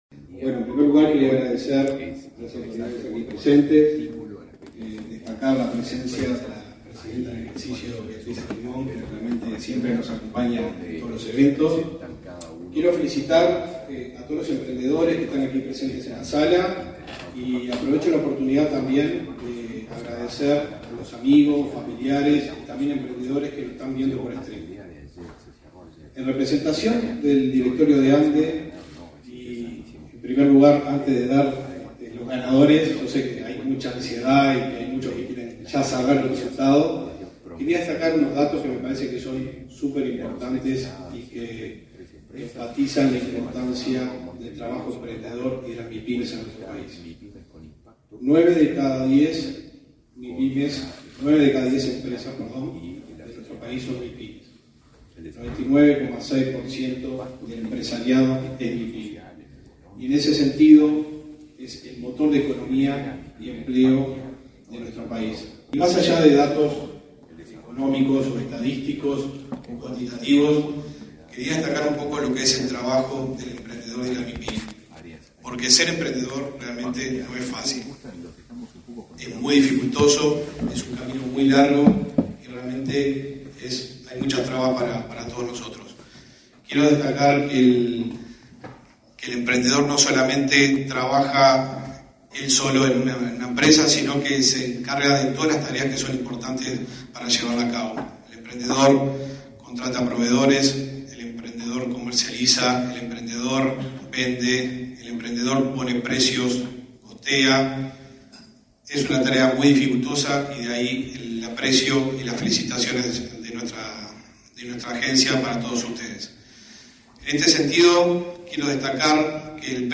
Palabras del director de la ANDE, Martín Ambrosi
La vicepresidenta en ejercicio de la Presidencia de la República, Beatriz Argimón, participó, este 25 de octubre, en la ceremonia del Premio MiPyme